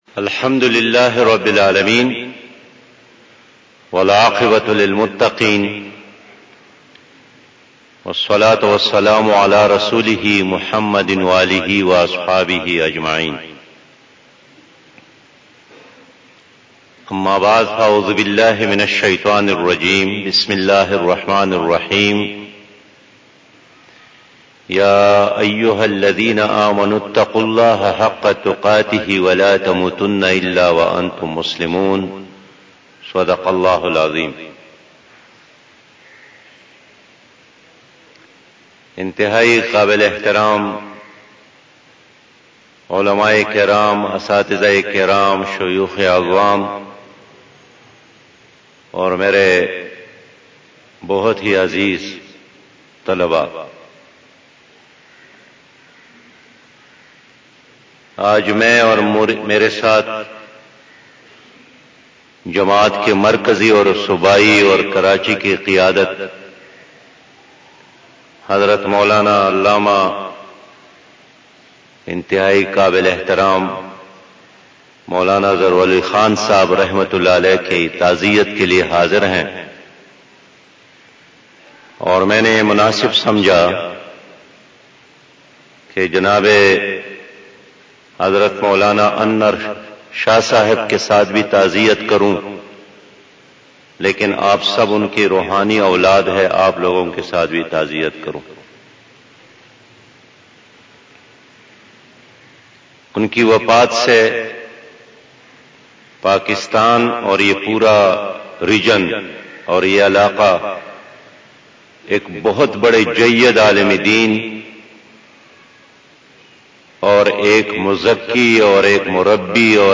20 Taaziyati Bayan Jnab Siraj ul Haq Shab 16 Dec 2020 (30 Rabi Us Sani 1442HJ) Monday تعزیتی بیان جناب سراج الحق صاحب امیر جماعت اسلامی Bayan from Others Ulama E Kiram 03 Feb, 21 - 08:25 PM 487 Others Bayanat 2020 --